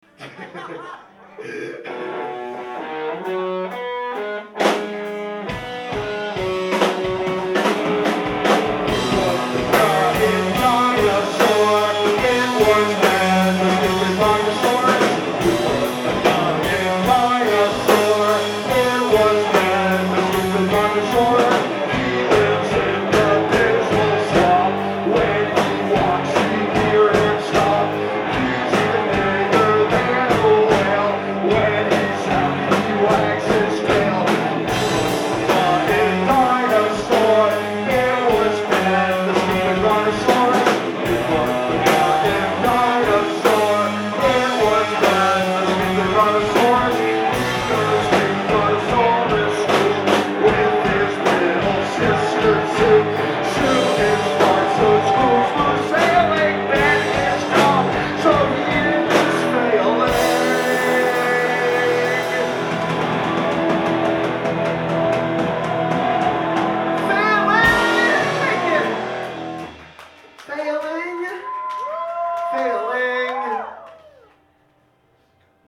Live at the Middle East Upstairs
in Cambridge, MA